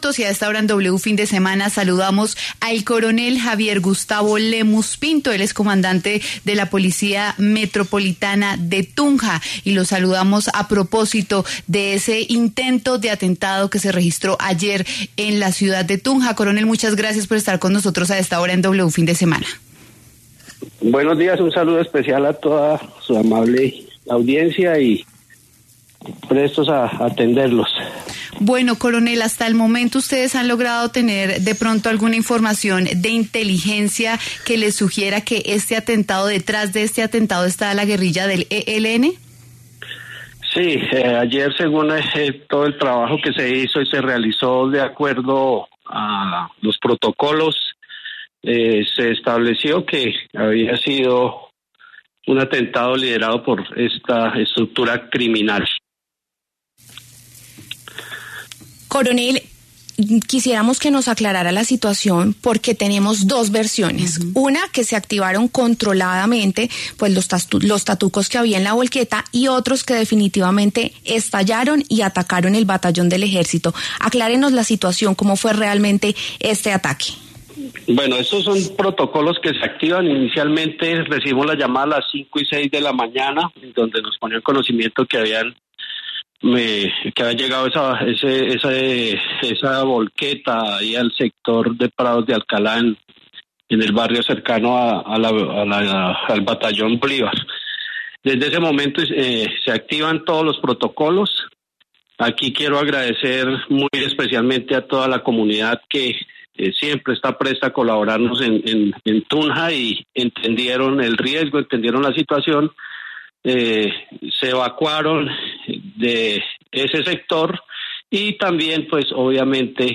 El coronel Javier Gustavo Lemus, comandante de la Policía Metropolitana de Tunja, se refirió en W Fin de Semana a la detonación de un camión abandonado que había sido cargado con explosivos.